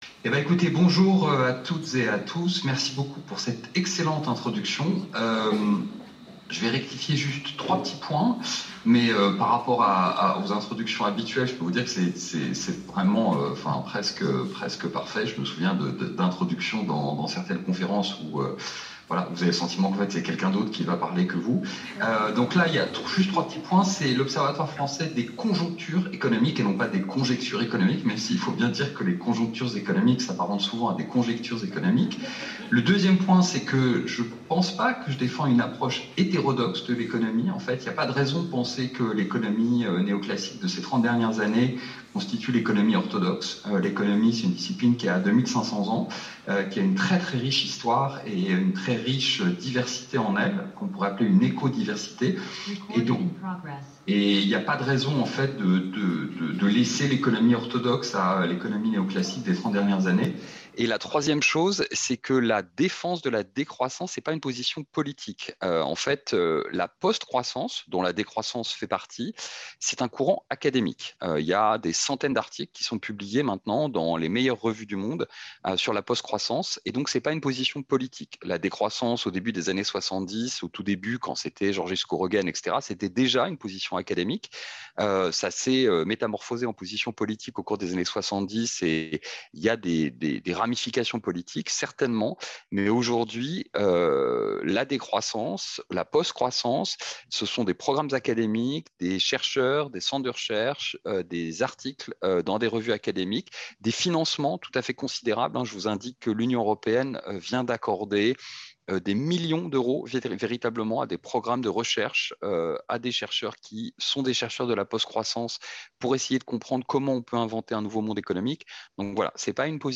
Conférence introductive